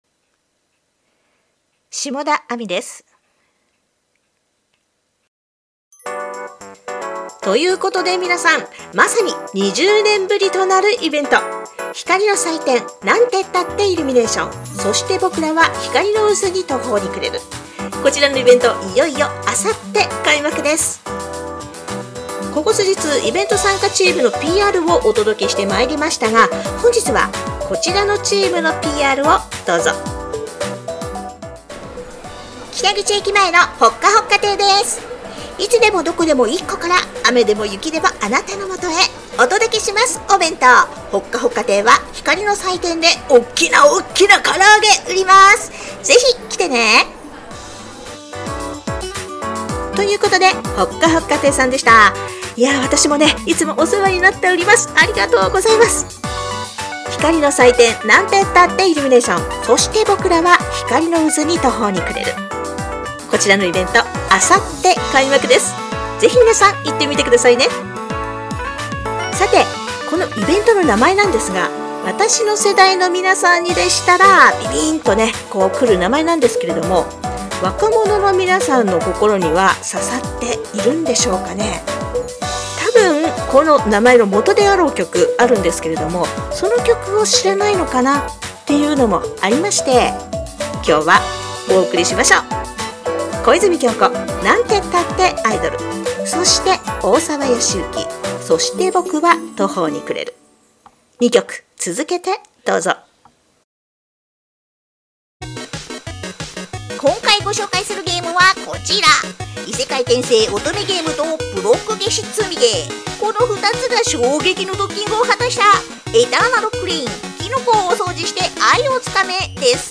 POPな元気で可愛い声